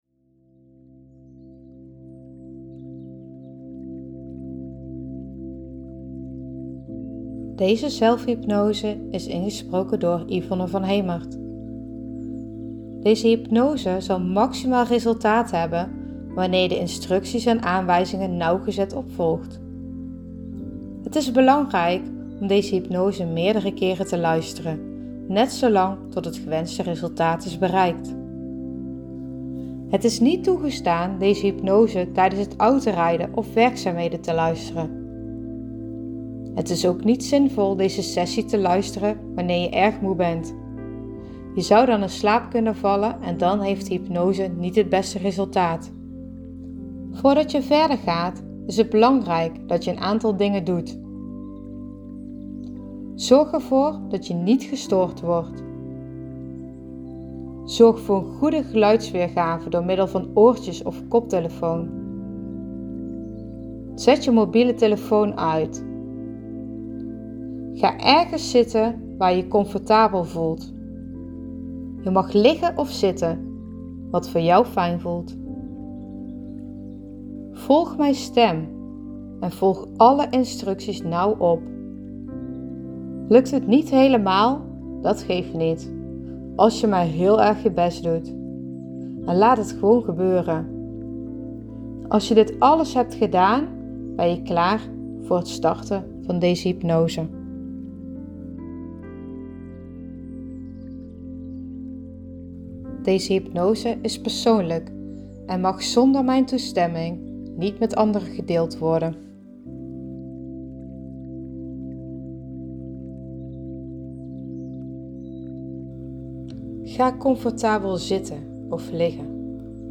Zelfhulp hypnose voorbeeld
Omdat het heel persoonlijk is of je een stemgeluid prettig vind of juist niet, vind je hier een voorbeeld mp3.